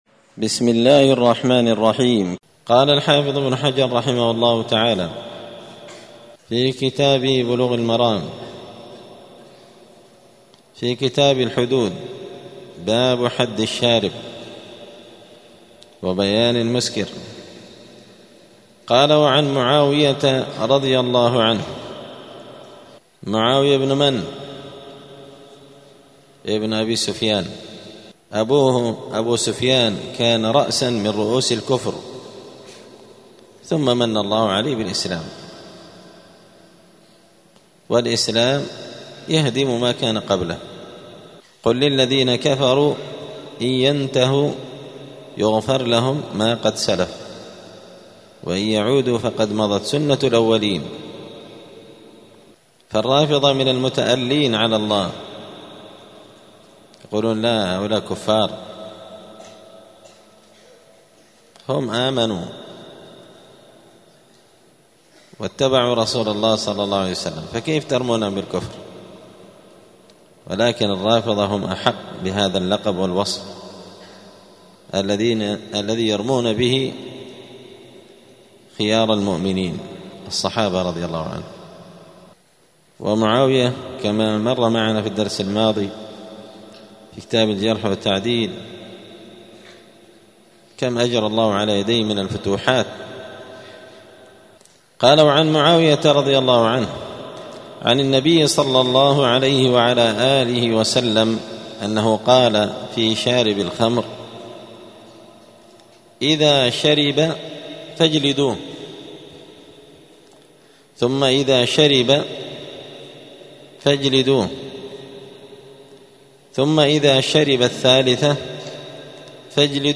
الأثنين 8 جمادى الآخرة 1446 هــــ | الدروس، سبل السلام شرح بلوغ المرام لابن الأمير الصنعاني، كتاب الحدود | شارك بتعليقك | 32 المشاهدات